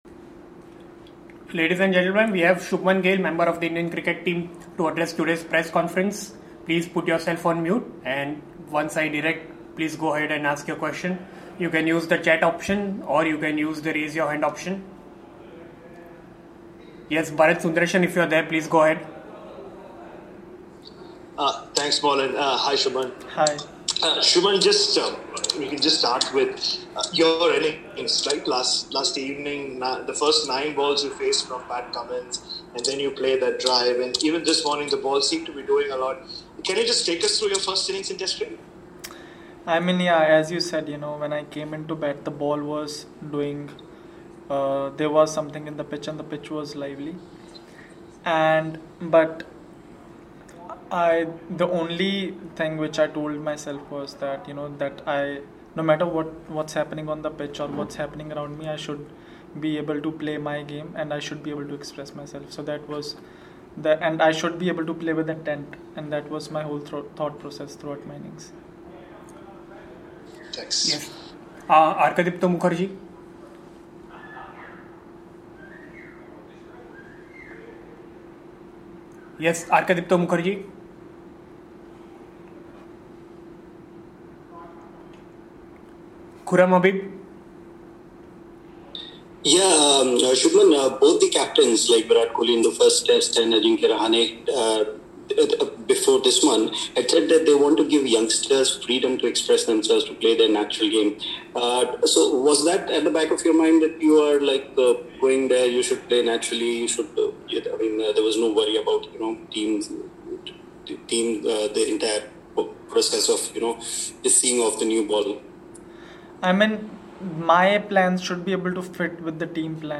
Shubman Gill addressed a virtual press conference after the second day’s play of the 2nd Border-Gavaskar Test against Australia in Melbourne.